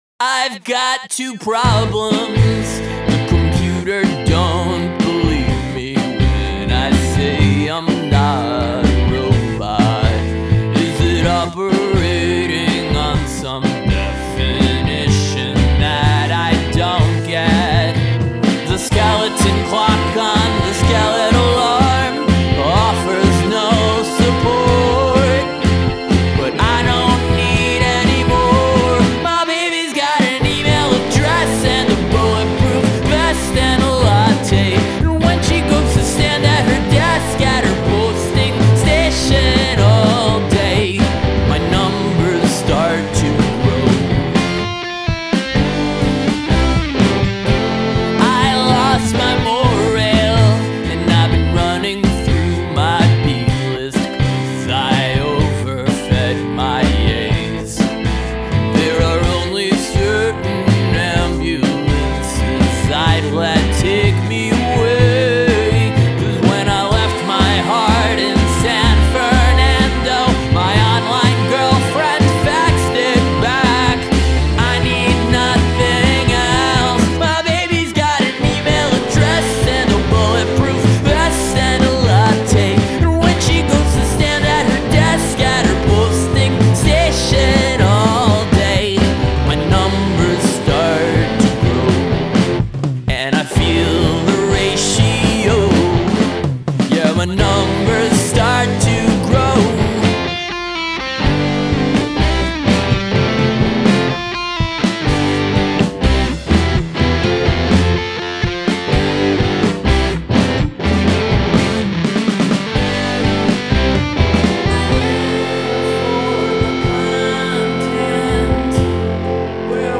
post-mcluhanist chamber punk for the cultural epidemic